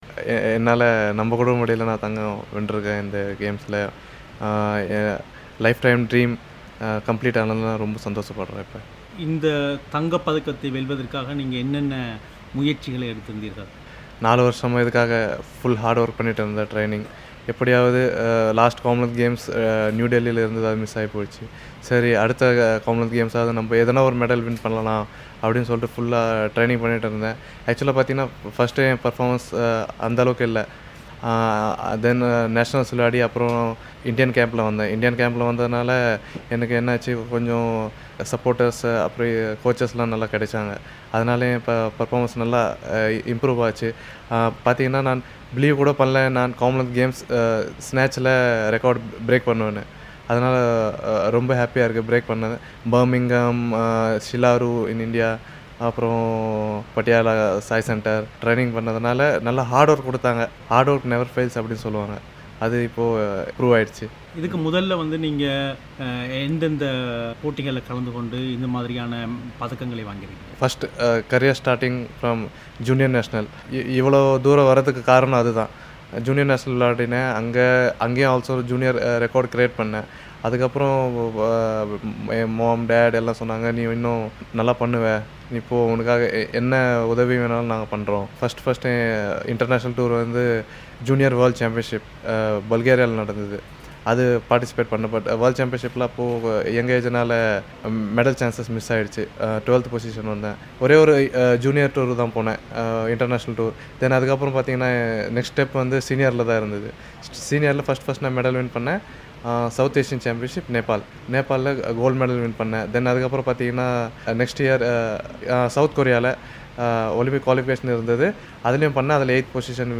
பிரத்தியேக நேர்காணல்